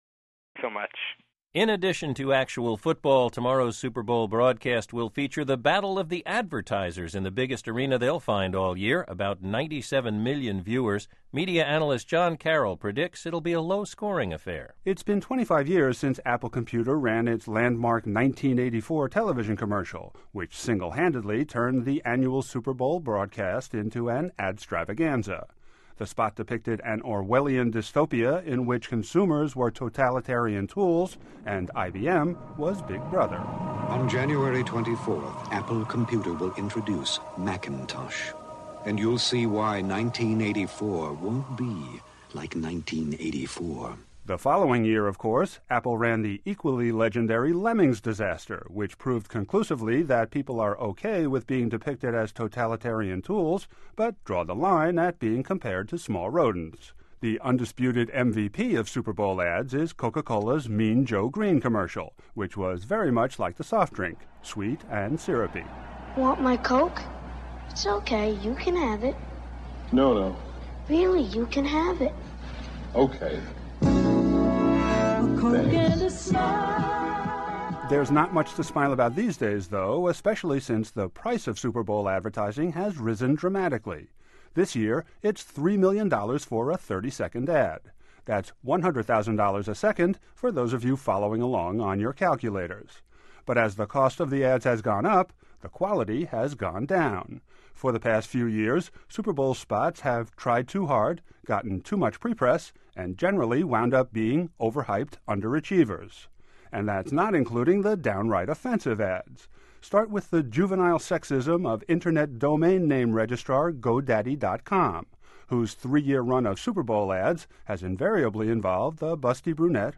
I began branching out at ‘BUR in 2009, starting with this Super Bowl ad preview I filed for Only a Game.
oag-2009-super-bowl-ads.mp3